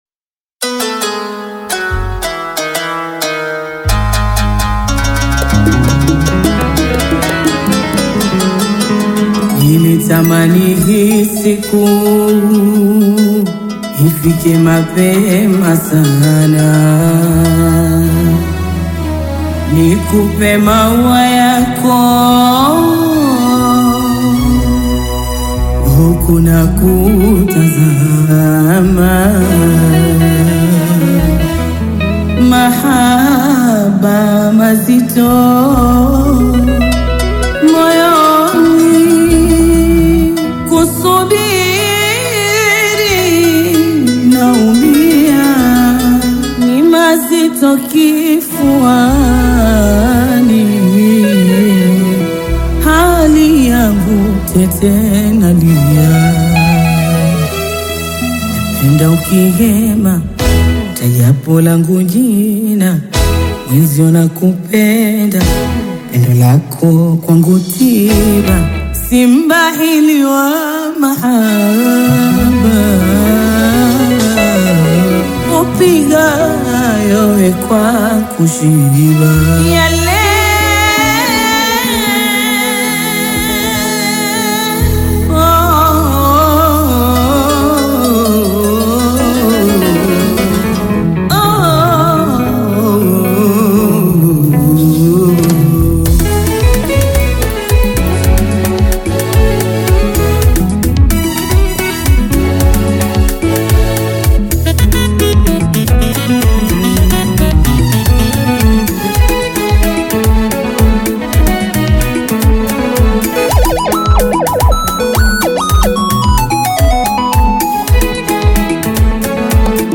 Taarab You may also like